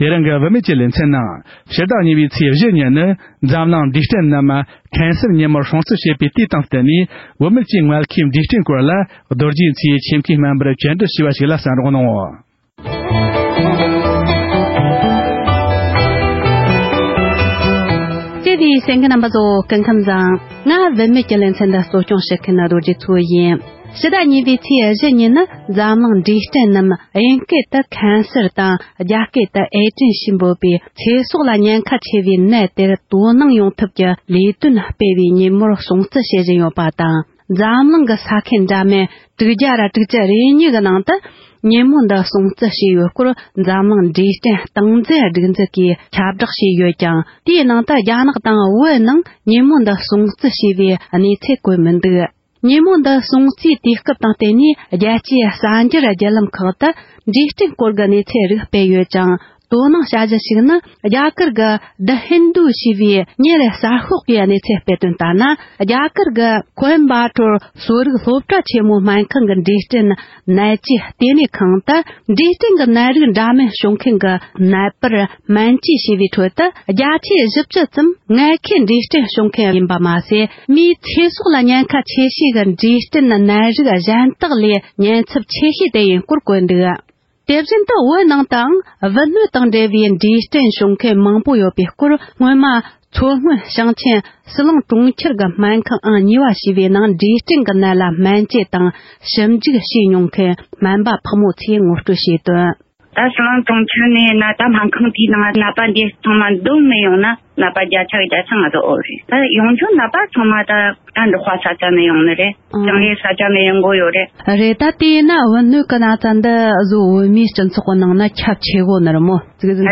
སྒྲ་ལྡན་གསར་འགྱུར།
ཆེད་མཁས་སྨན་པར་བཅར་འདྲི་བྱས་པ་ཞིག